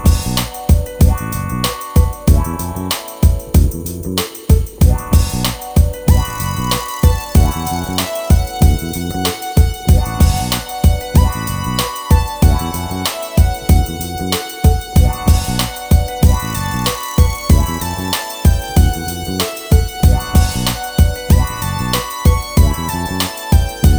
no Backing Vocals Dance 3:52 Buy £1.50